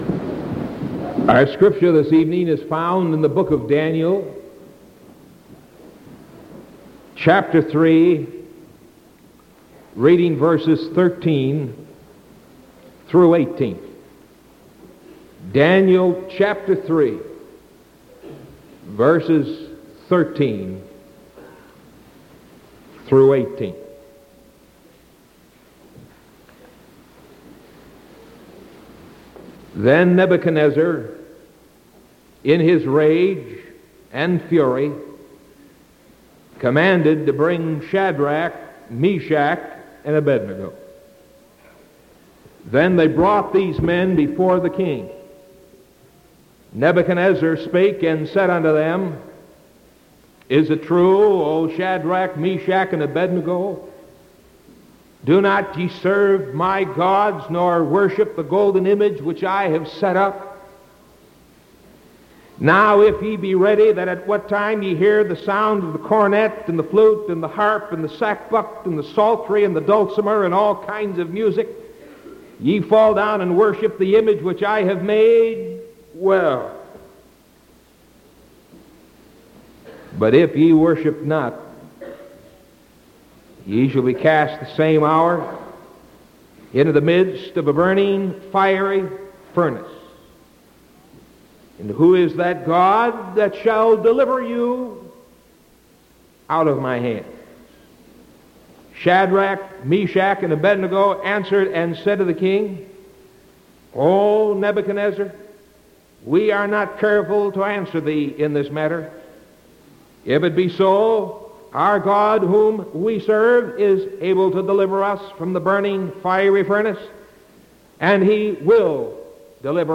Sermon Spetember 22nd 1974 PM